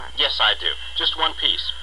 (加連線者為連音，加網底者不需唸出聲或音很弱。)